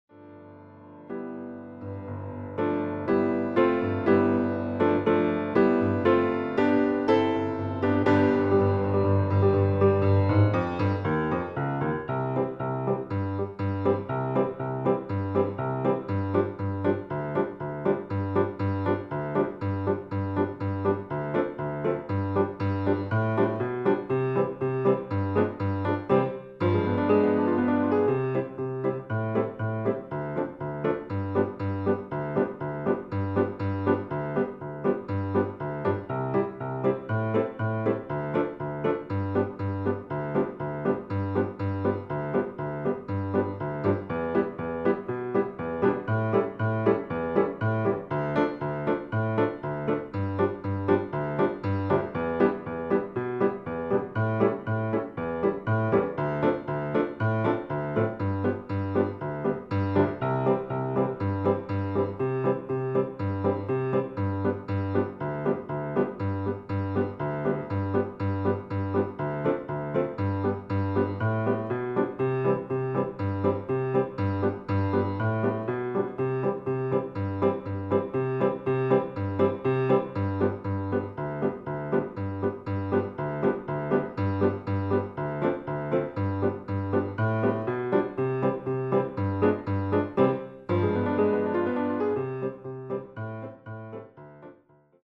• Tonart: C Dur
• Art: Flügeleinspielung
• Das Instrumental beinhaltet keine Leadstimme
Lediglich die Demos sind mit einem Fade-In/Out versehen.
Klavier / Streicher